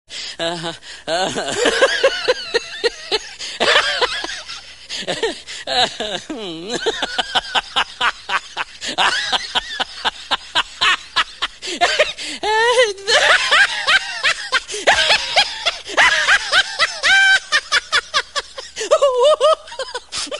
angry dog sound effects free download